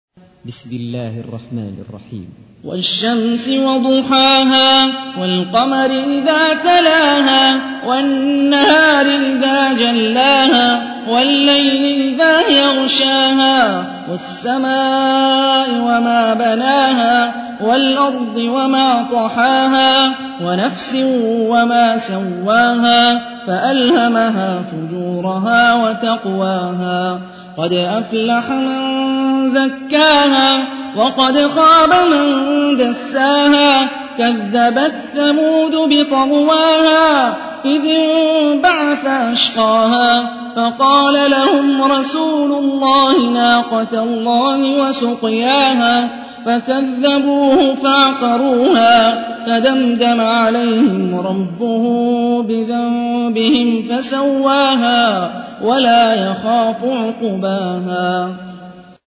تحميل : 91. سورة الشمس / القارئ هاني الرفاعي / القرآن الكريم / موقع يا حسين